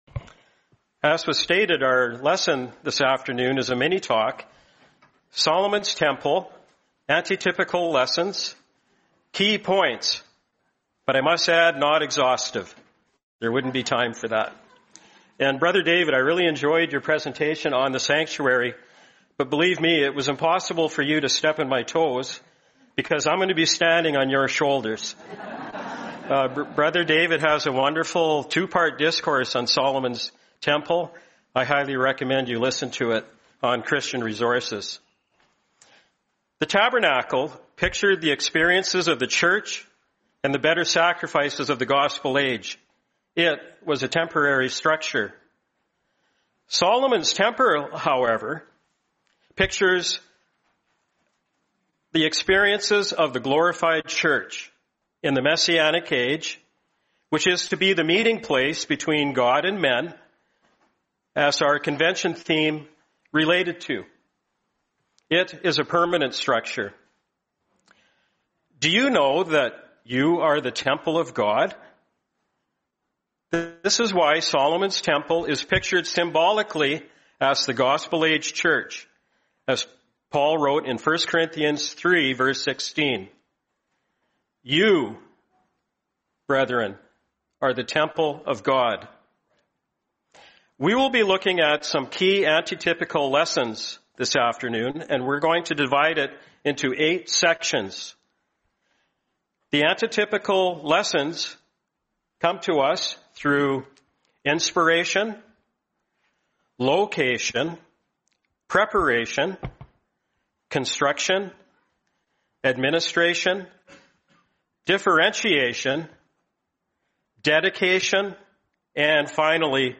Series: 2025 Florida Convention